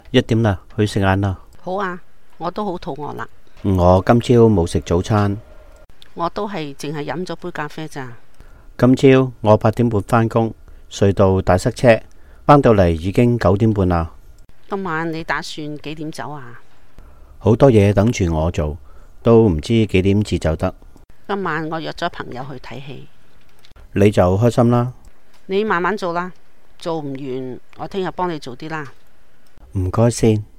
BC L6 V2: Two colleagues in their office